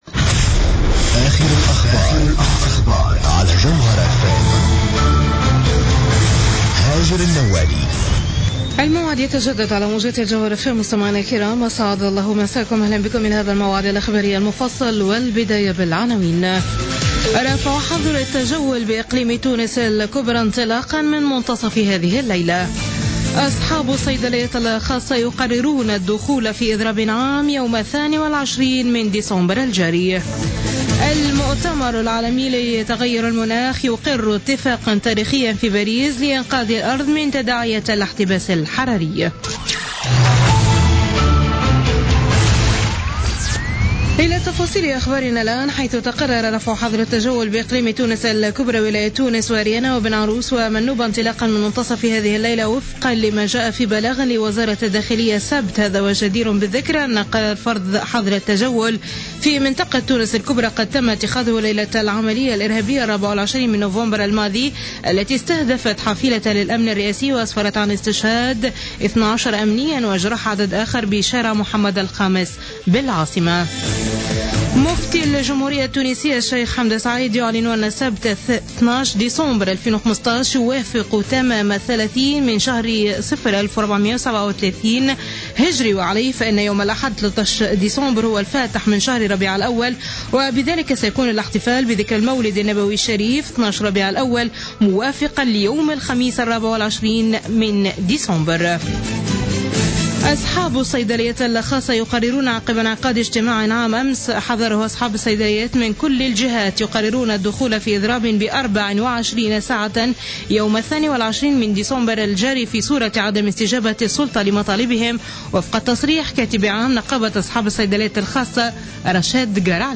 نشرة أخبارمنتصف الليل ليوم الأحد 13 ديسمبر 2015